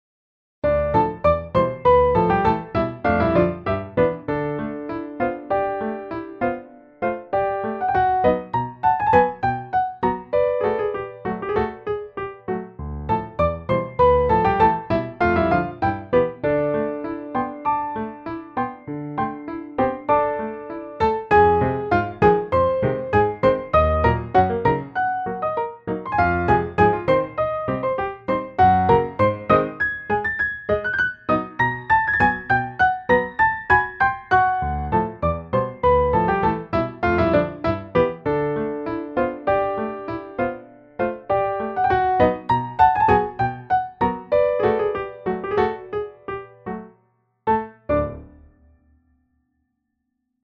Piano duet